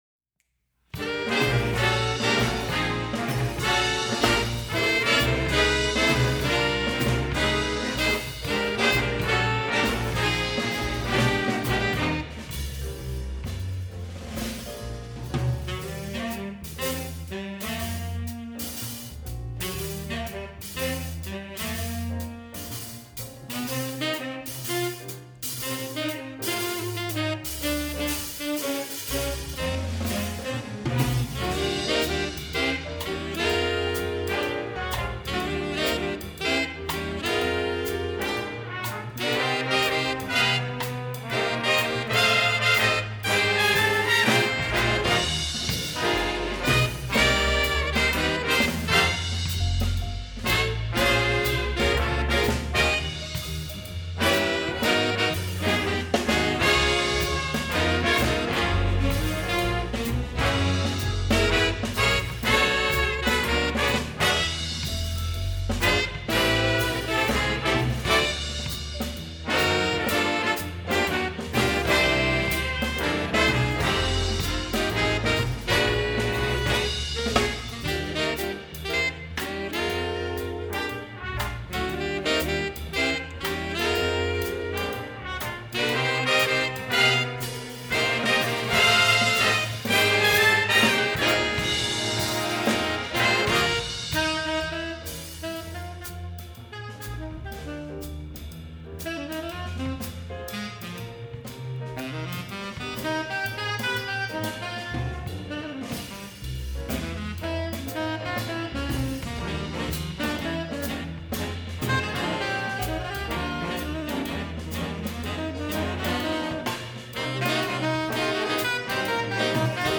swing tune